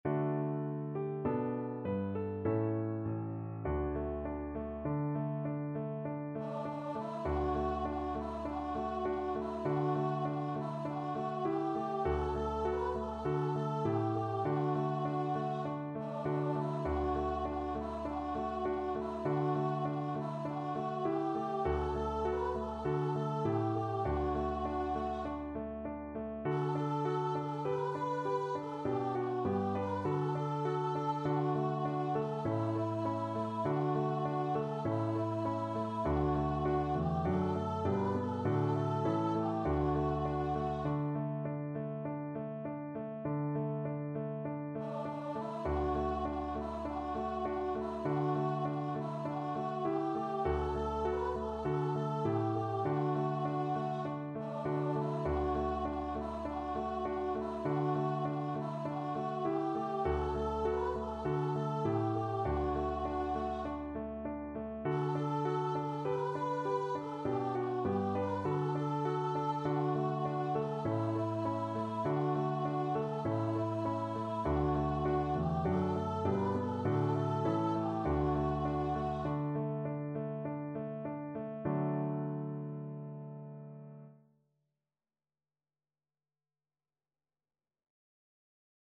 Christmas Christmas Voice Sheet Music Es ist für uns eine Zeit angekommen
Voice
D major (Sounding Pitch) (View more D major Music for Voice )
Moderato
Traditional (View more Traditional Voice Music)